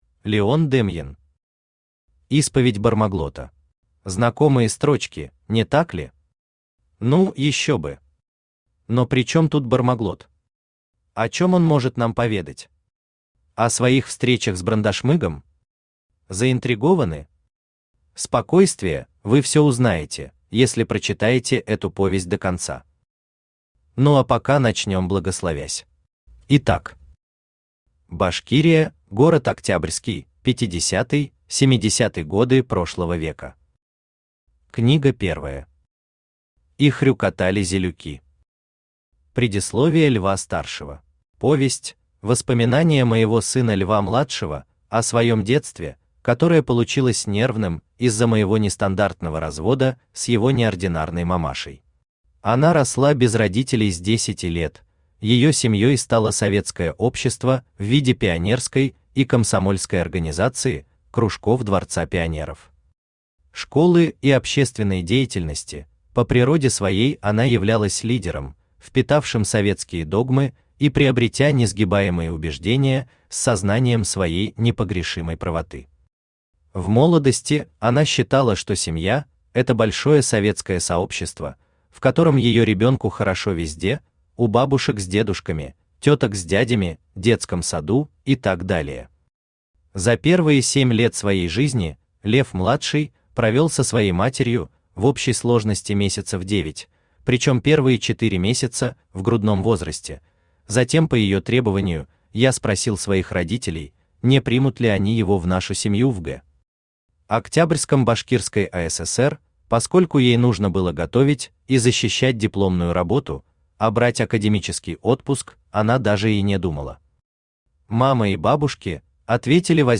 Аудиокнига Исповедь Бармаглота | Библиотека аудиокниг
Aудиокнига Исповедь Бармаглота Автор Леон Дэмьен Читает аудиокнигу Авточтец ЛитРес.